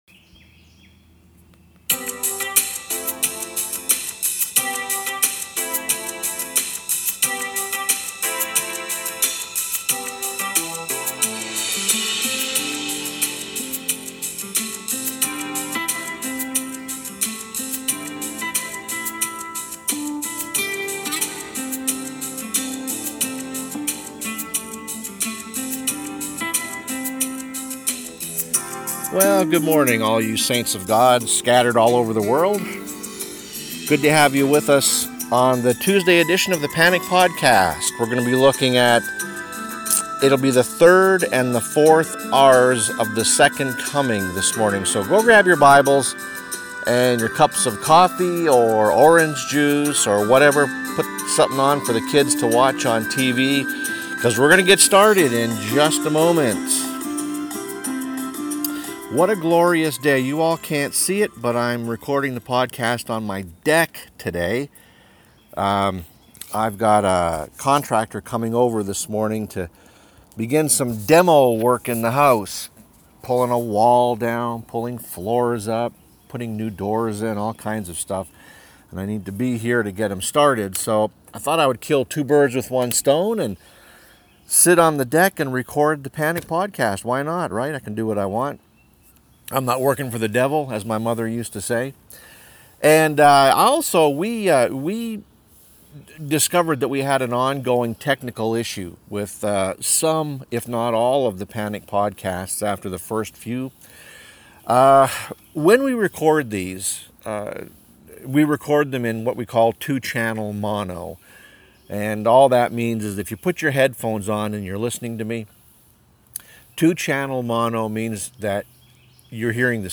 Yes, it's me, sitting on my deck this morning.